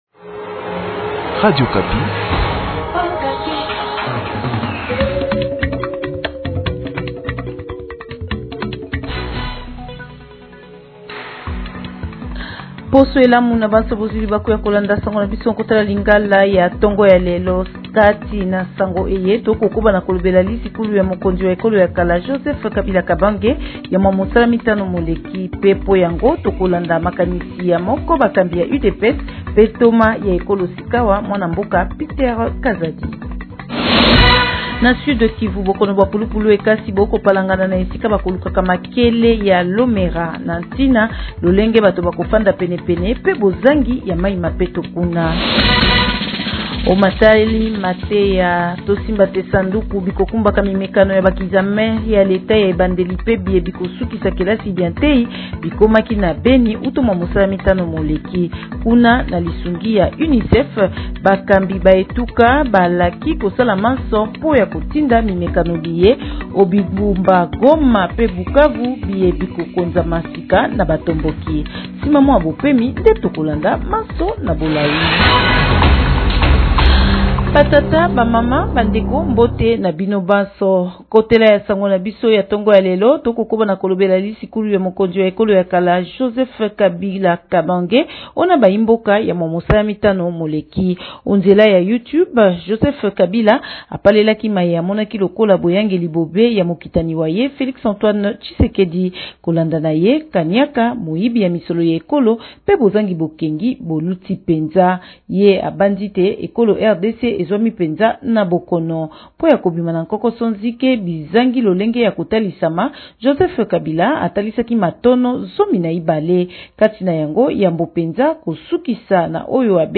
Journal Lingala Matin